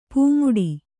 ♪ pūmuḍi